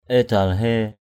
/e̞-d̪al-he̞:/ (t.) quá xá = (superlatif) très, extrêmement. extremely. mada édalhé md% edL_h^ giàu quá xá = extrêmement riche. extremely rich.